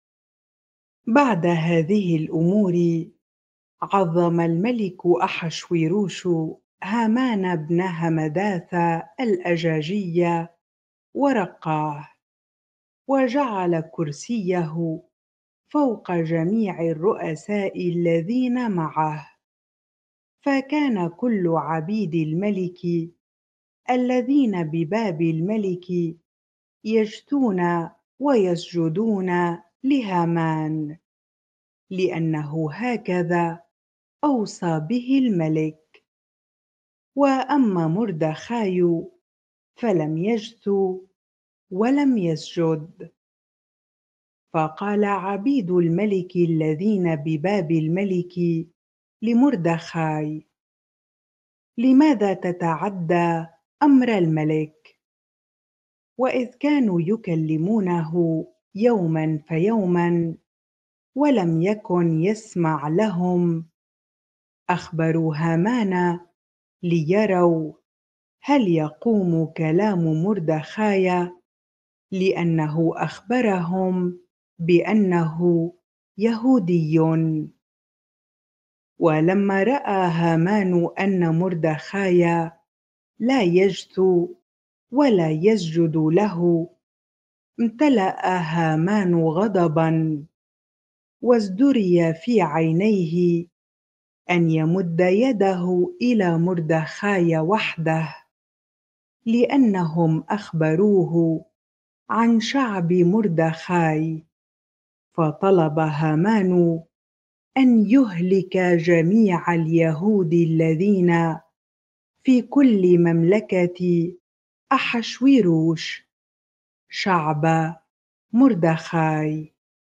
bible-reading-Esther 3 ar